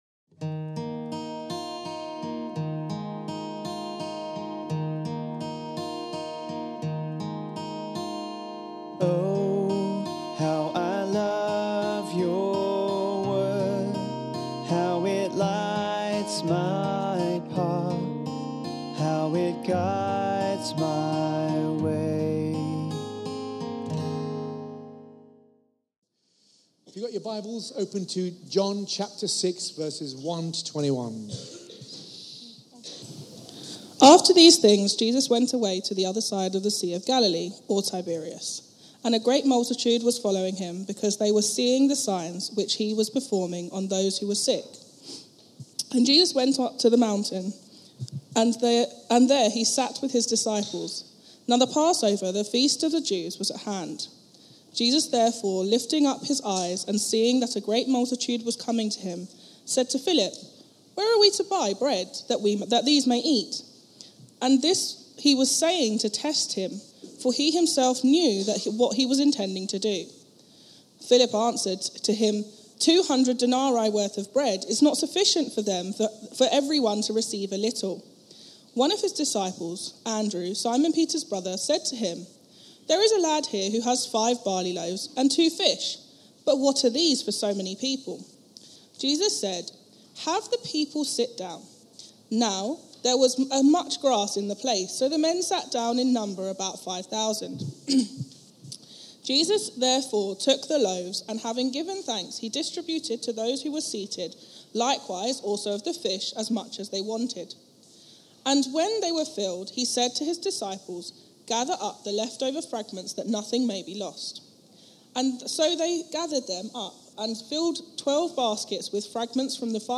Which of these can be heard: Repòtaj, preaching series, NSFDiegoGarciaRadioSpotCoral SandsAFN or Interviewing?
preaching series